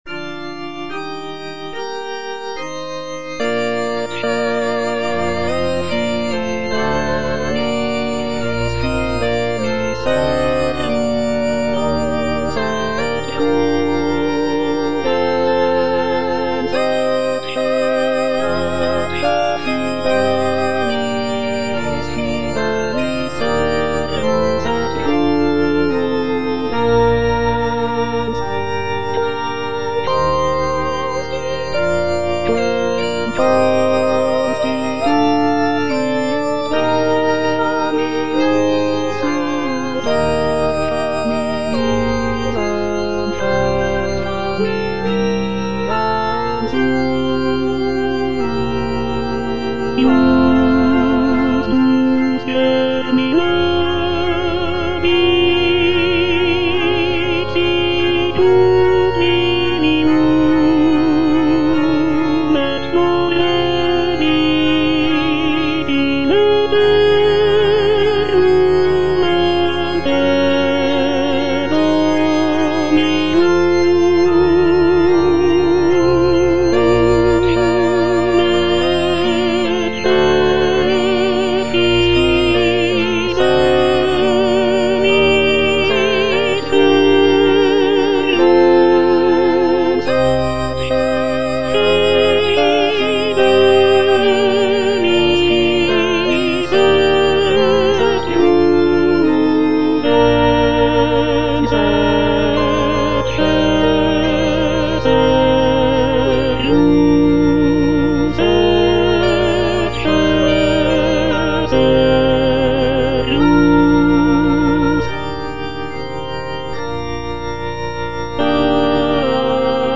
G. FAURÉ - ECCE FIDELIS SERVUS Tenor (Emphasised voice and other voices) Ads stop:
"Ecce fidelis servus" is a sacred choral work composed by Gabriel Fauré in 1896. The piece is written for four-part mixed choir and organ, and is based on a biblical text from the Book of Matthew. The work is characterized by Fauré's trademark lyrical melodies and lush harmonies, creating a serene and contemplative atmosphere.